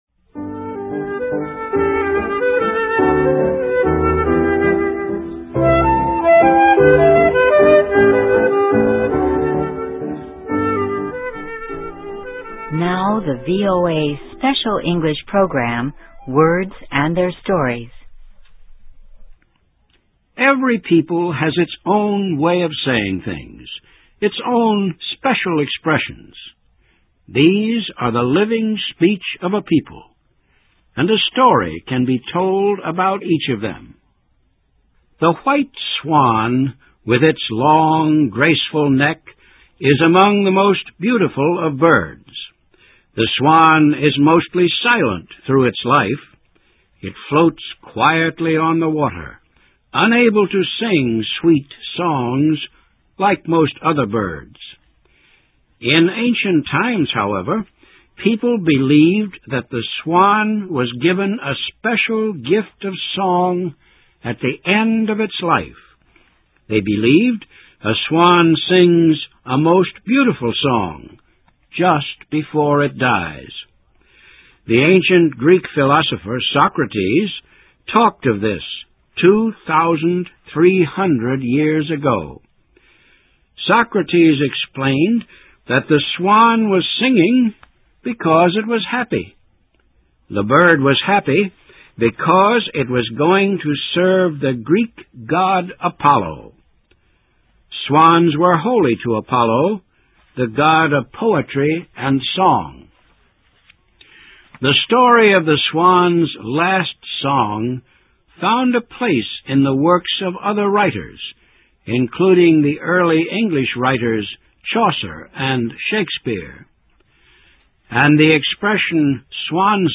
Words and Their Stories: Swan Song: Swan Song (It Means a Person's Final Effort) (VOA Special English 2007-10-01)